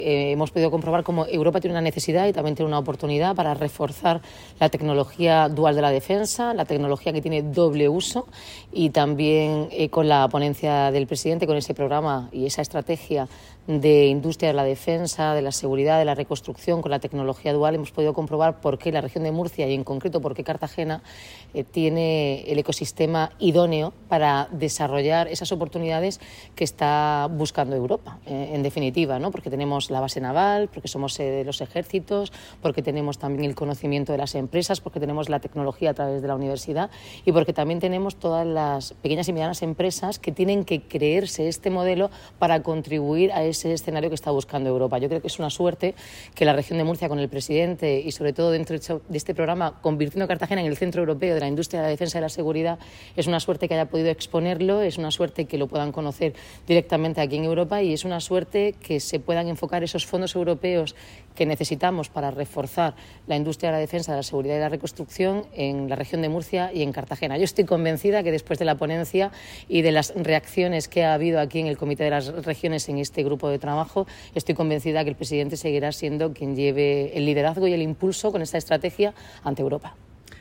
Enlace a Declaraciones de la alcaldesa, Noelia Arroyo